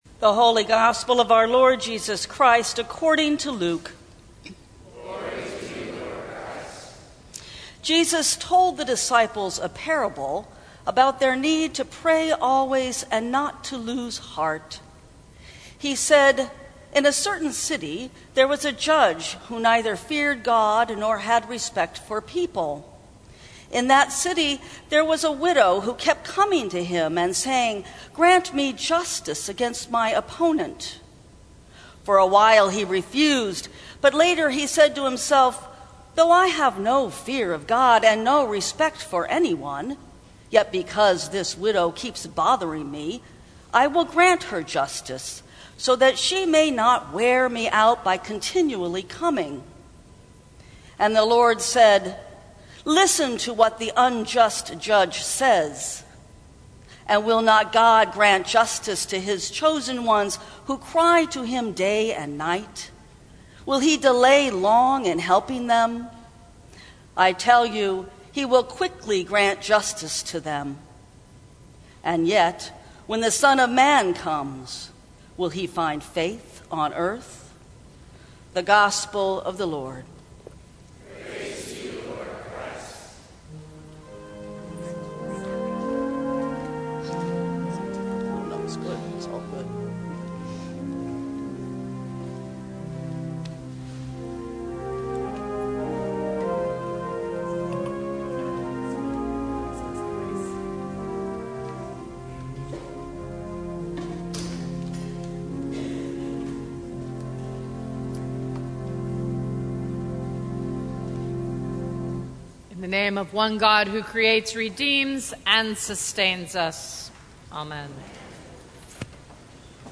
Sermons from St. Cross Episcopal Church Judge or Widow?